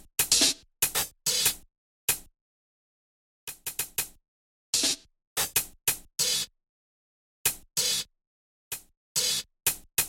Tag: 95 bpm Electronic Loops Drum Loops 1.70 MB wav Key : Unknown